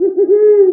A_OWL2.mp3